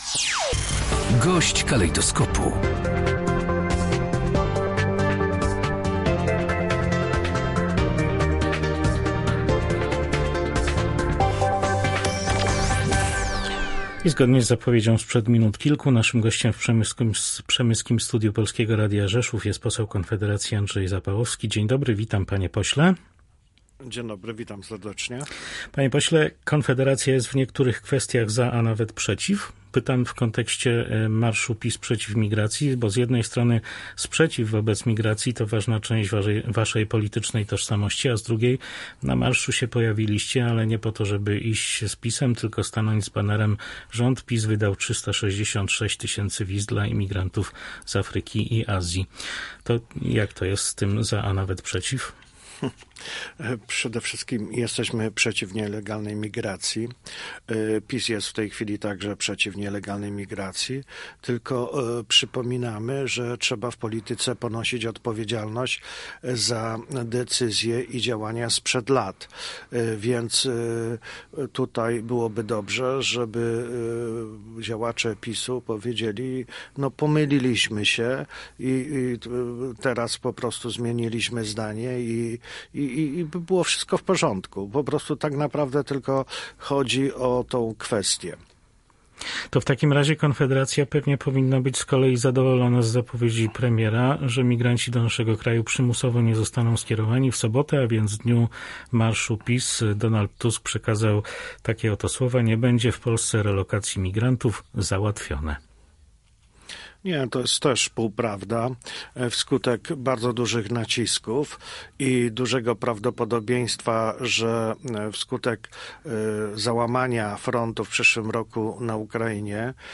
– mówi poseł Konfederacji Andrzej Zapałowski.